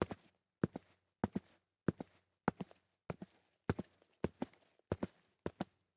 Player Character SFX